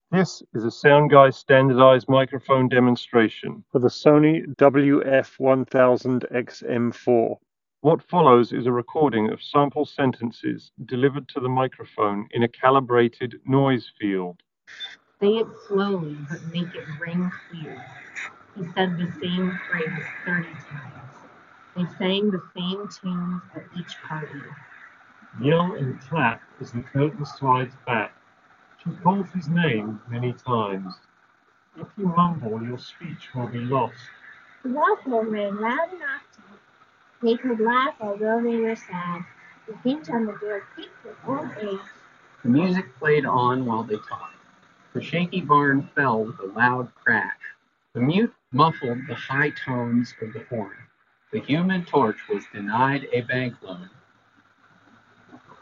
Sony-WF-1000XM4_Street-microphone-sample.mp3